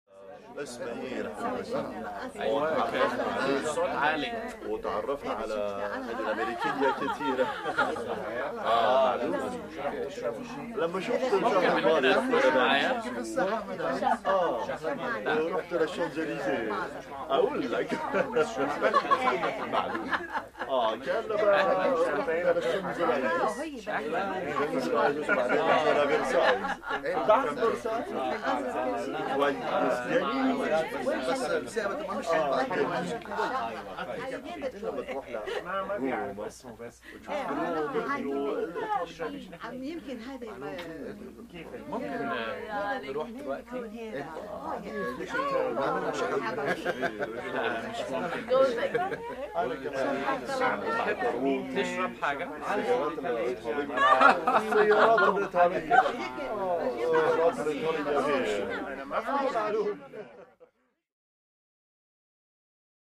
Arabic Walla With Pointed Lines And Laughter